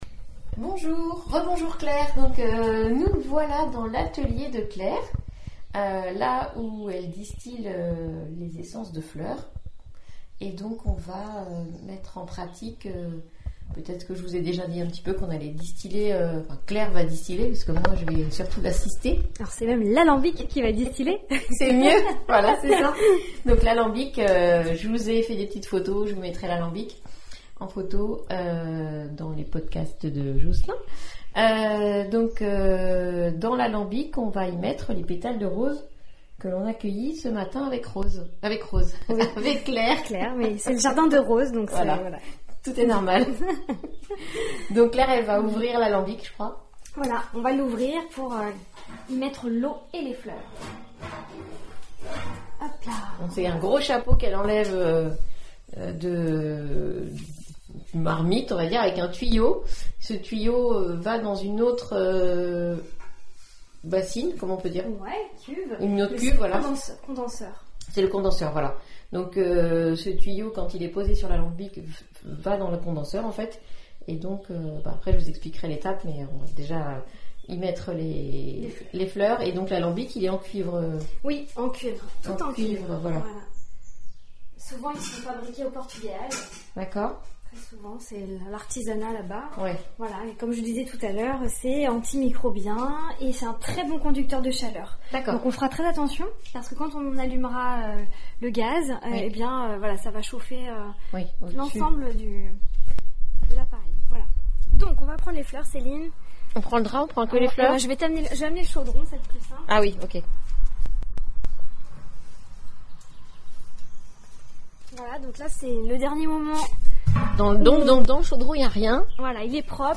reportage au Piton du Dehors à Mont-Saint-Jean